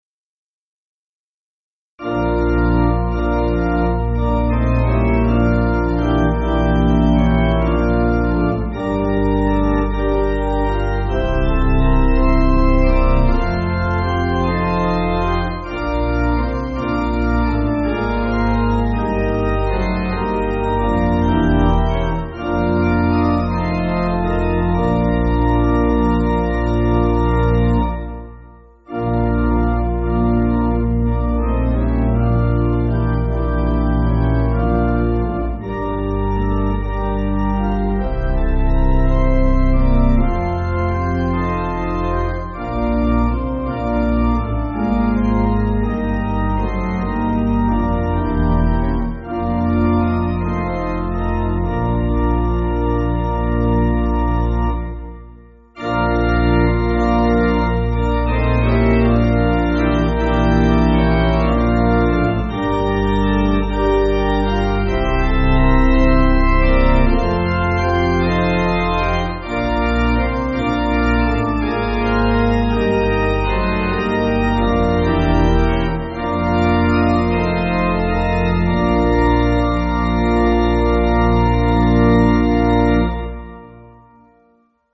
Organ
(CM)   3/Bb